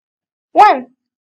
Wàn / goan) : Vạn